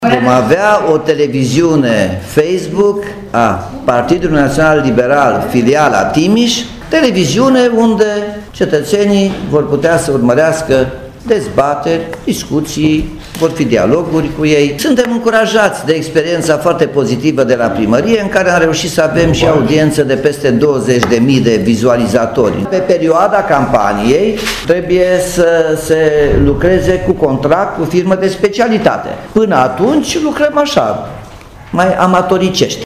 Primarul Nicolae Robu a anunțat astăzi, în cadrul unei conferințe de presă, unei televiziuni a partidului, pe Facebook.